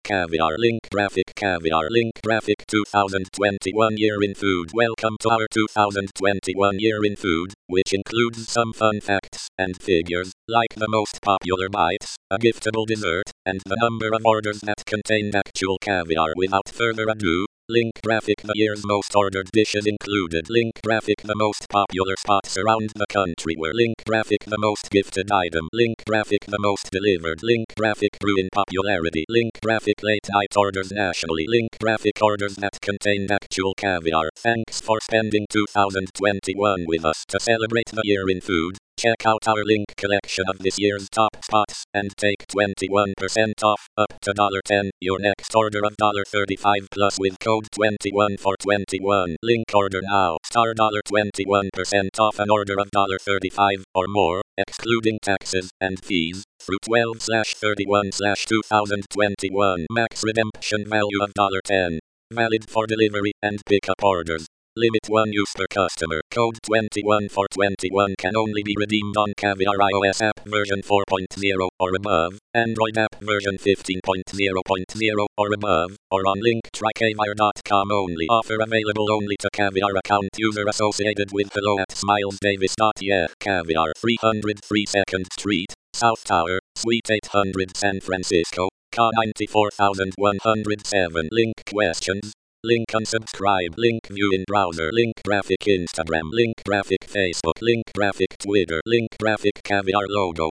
Text to voice recording and transcript for hearing impaired.